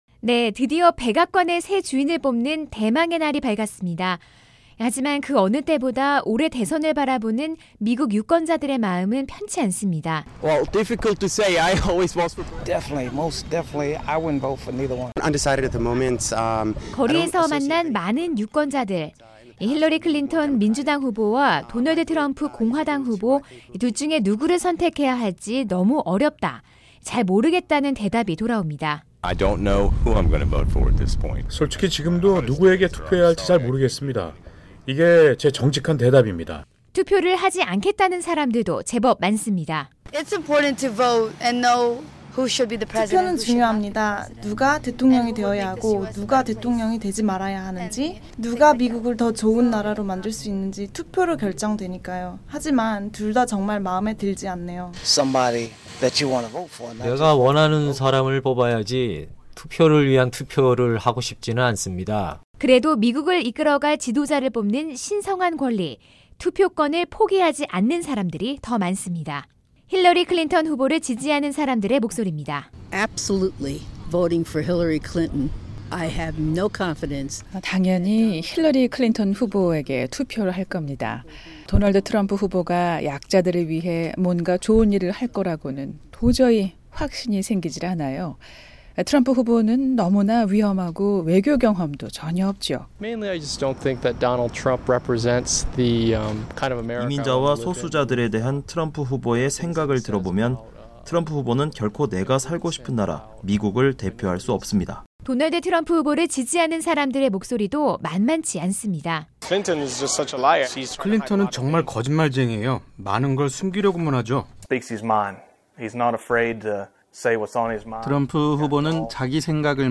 [대선특집] 미국 유권자와의 인터뷰
미국의 45대 대통령을 선출하기 위한 투표가 8일 전국적으로 열리고 있습니다. 이번에는 미국 유권자들의 목소리를 들어보겠습니다.